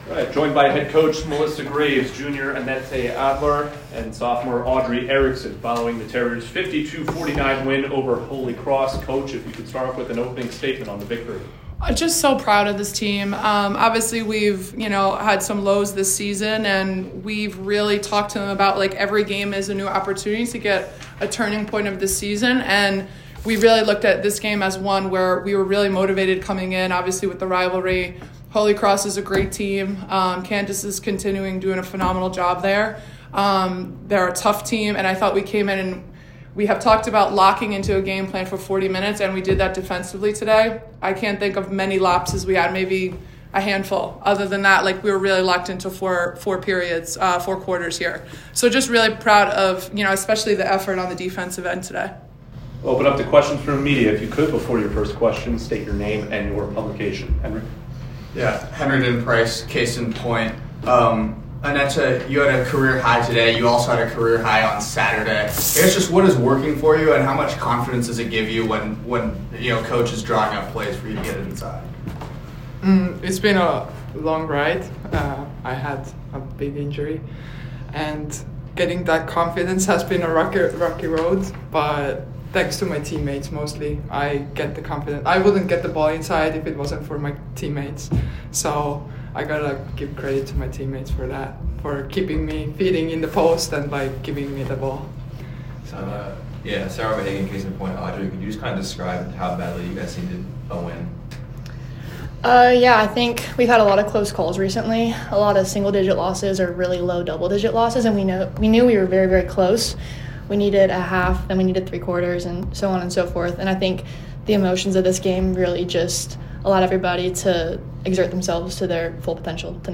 WBB_Holy_Cross_1_Postgame.mp3